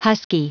Prononciation du mot husky en anglais (fichier audio)
Prononciation du mot : husky